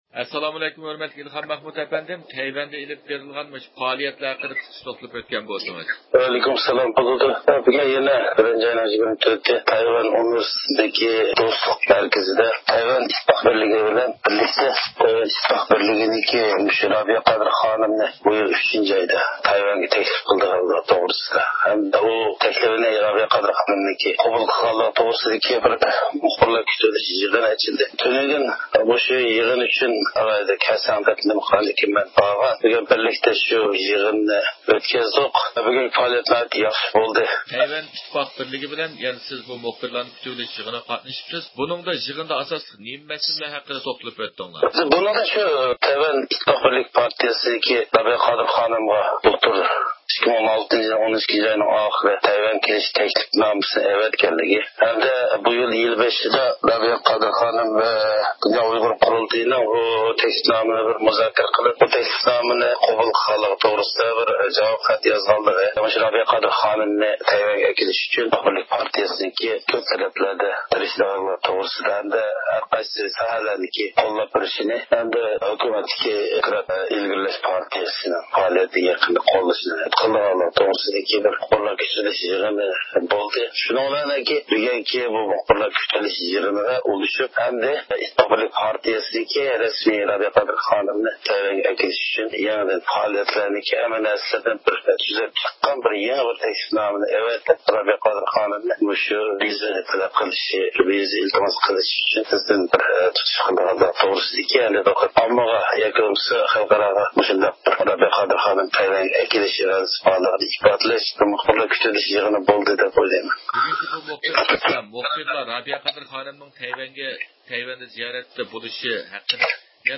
نەق مەيدان تېلېفون سۆھبىتىمىزدە بولغاي